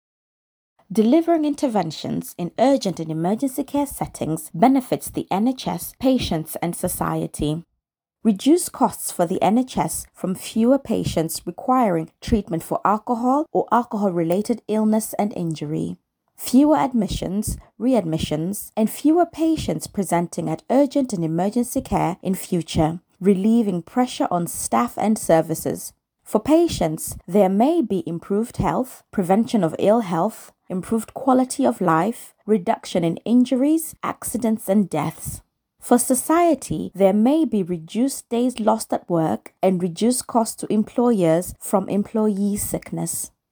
Scales - Resource One Narration audio (MP3) Narration audio (OGG) Hide Text Menu 26% Complete 4.